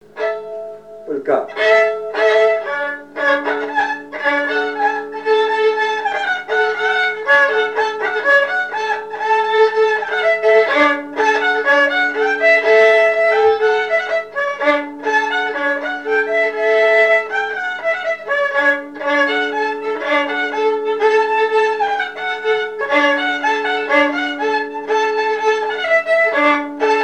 Mémoires et Patrimoines vivants - RaddO est une base de données d'archives iconographiques et sonores.
danse : polka
Pièce musicale inédite